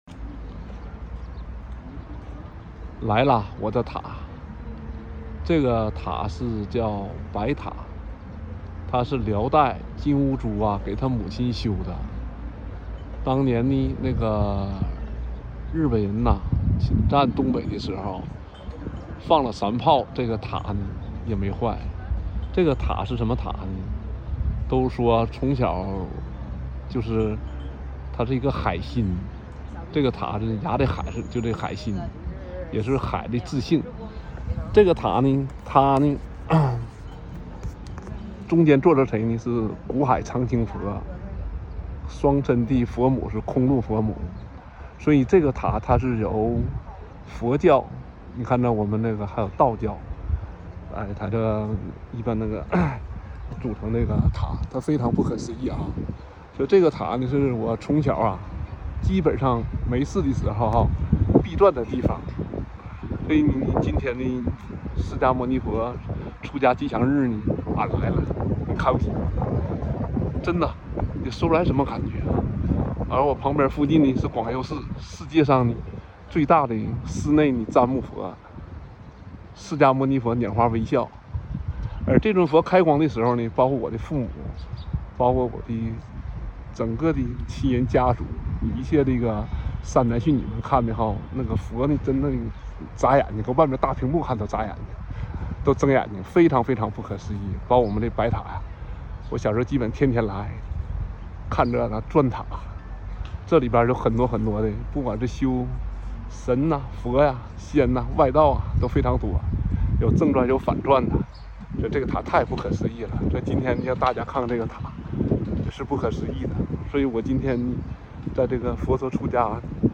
回归童年塔的家园-圣尊师爷亲自语音开示加持！大家要珍惜.mp3